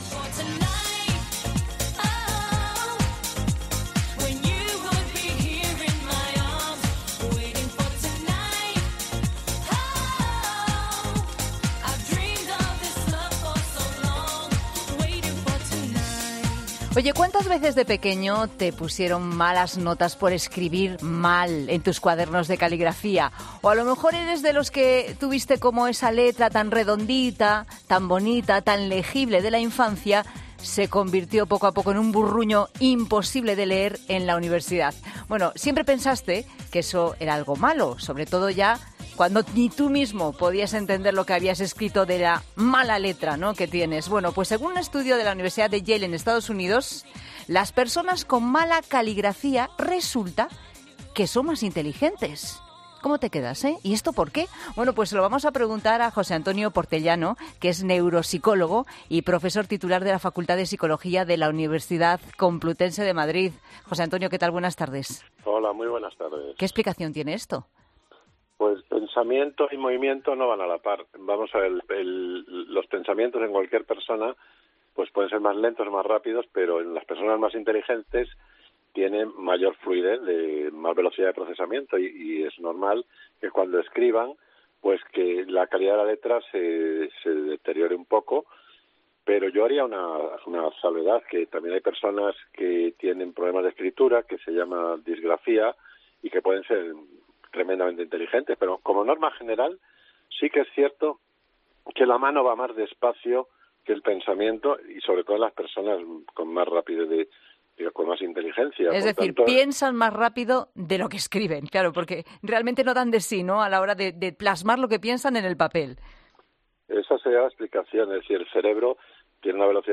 En 'La Tarde' de COPE hablamos con un neuropsicólogo para que nos detalle los resultados de este análisis